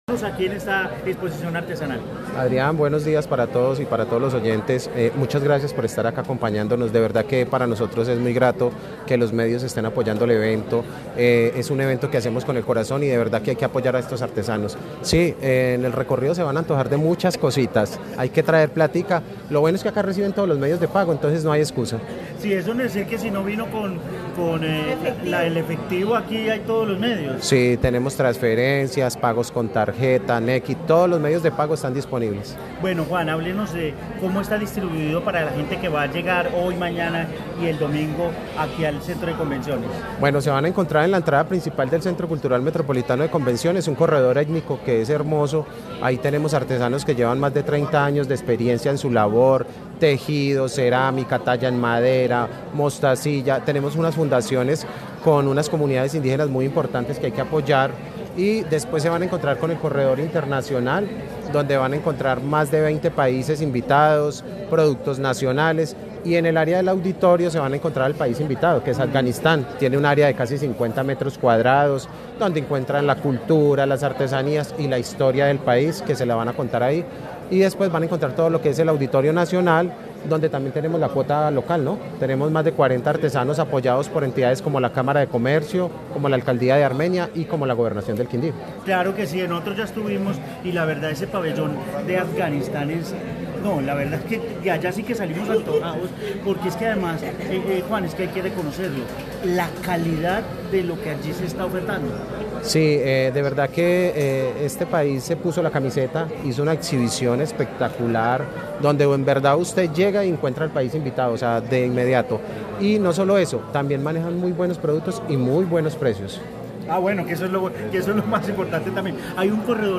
El noticiero de mediodía de Caracol Radio Armenia originamos desde el Centro de Convenciones donde se lleva a cabo la XI Exposición Artesanal, el Origen del Arte Hecho a Mano que se extenderá hasta el domingo 4 de mayo con la presencia de 200 artesanos locales, nacionales e internacionales.